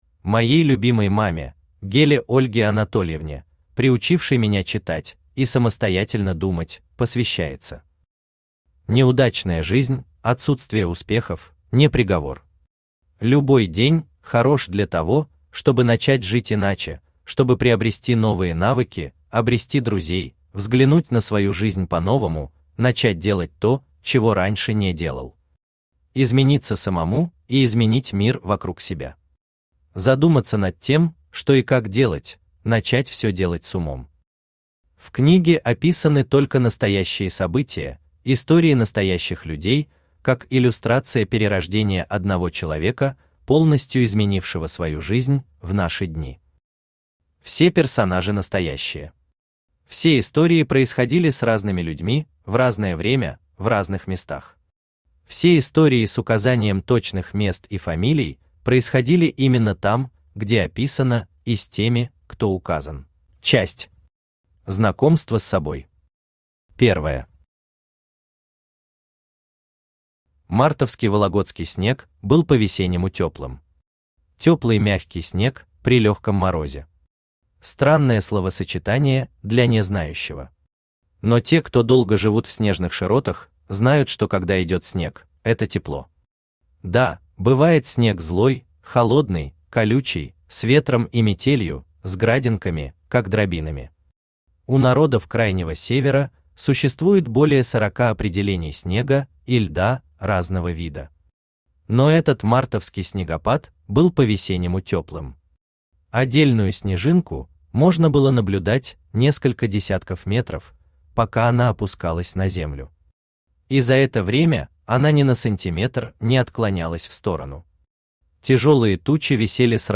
Аудиокнига Второе рождение | Библиотека аудиокниг
Aудиокнига Второе рождение Автор Антон Николаевич Геля Читает аудиокнигу Авточтец ЛитРес.